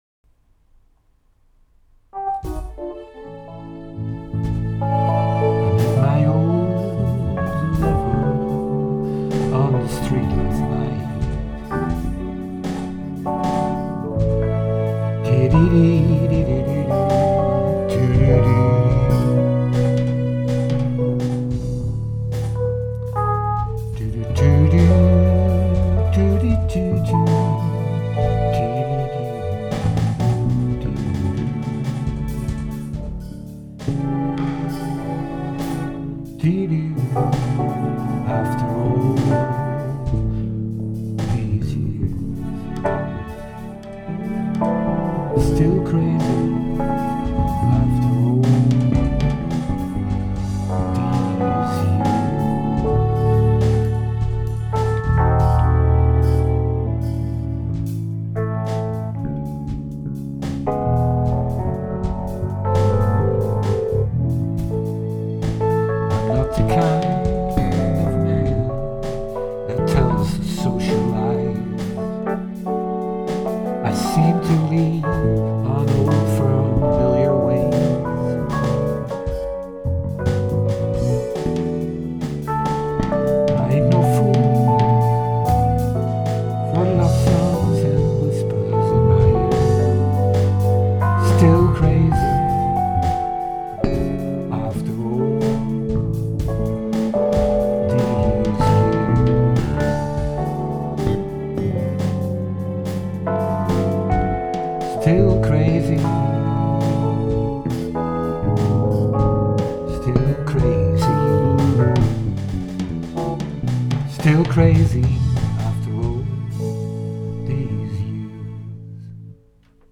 "cover" version